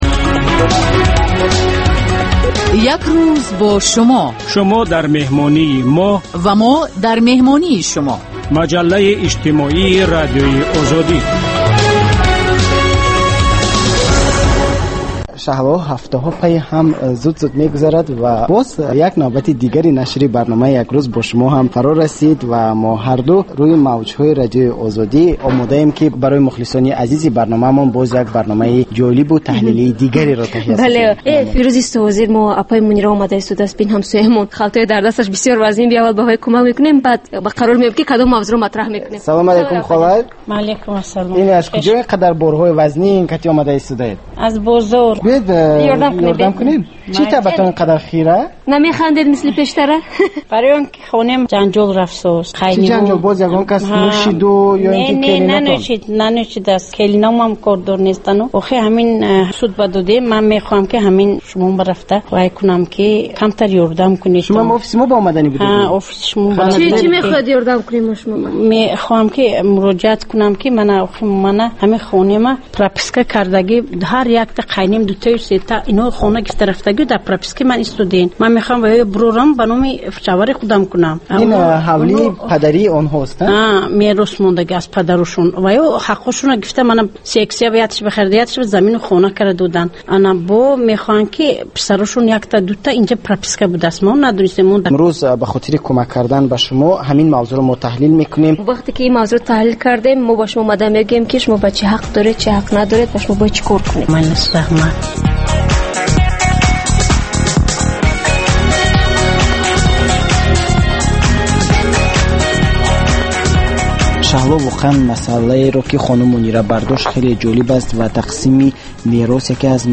Маҷаллаи ғайриодӣ, ки ҳамзамон дар шакли видео ва гуфтори радиоӣ омода мешавад.